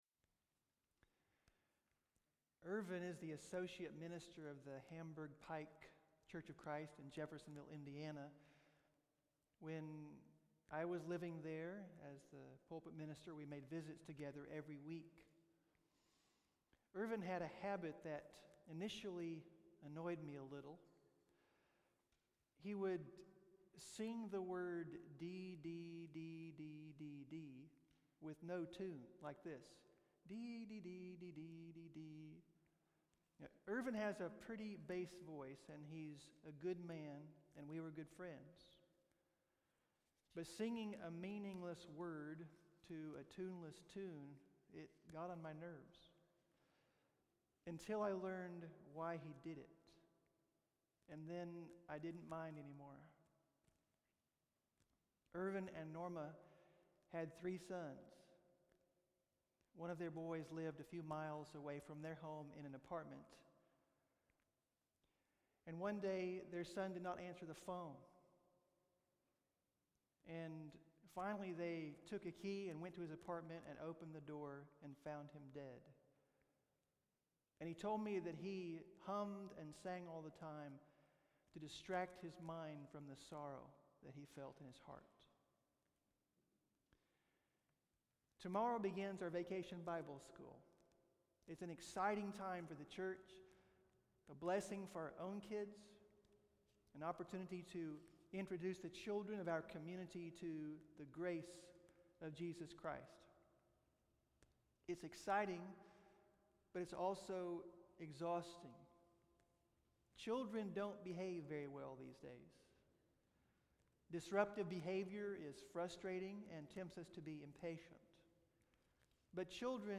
Prayer Service To Bless Our VBS (pm)